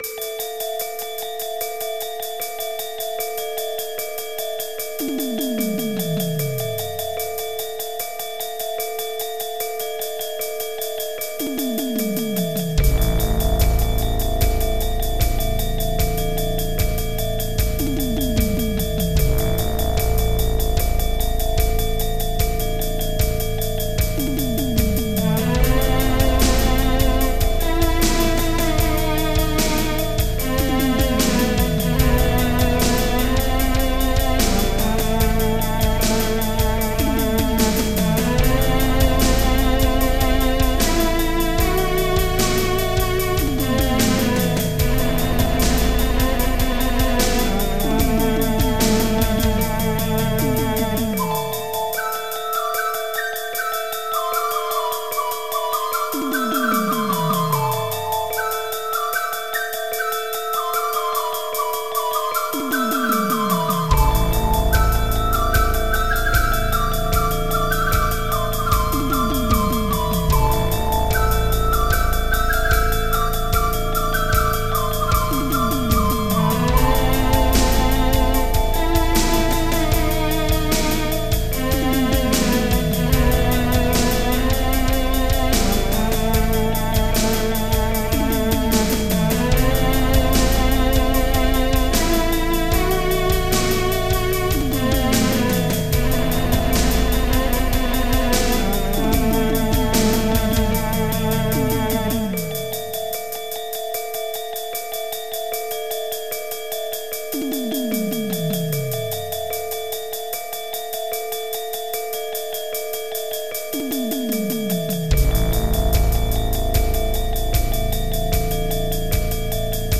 MIDI Music File
Copyright (c Type General MIDI